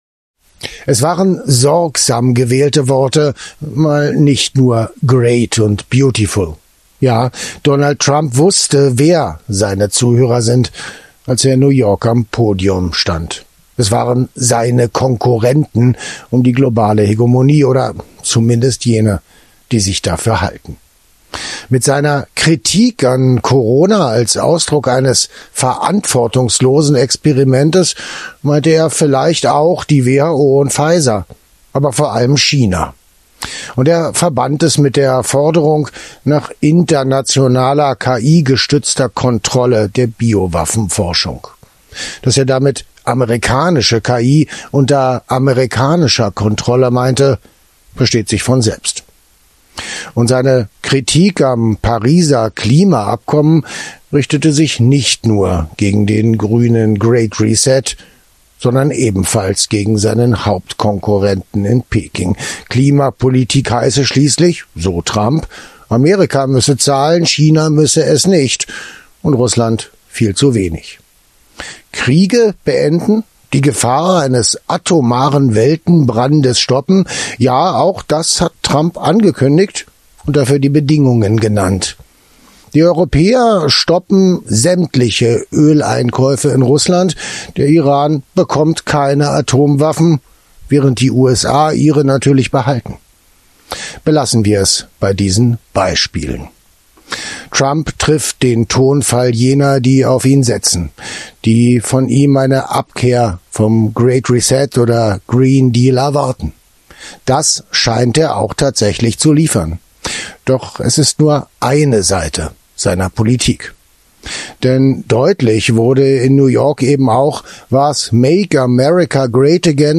Kommentar: Trumps Rede – Neuer Kurs oder alter Imperialismus?